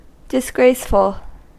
Ääntäminen
IPA : [dɪsˈɡɹeɪsfəɫ]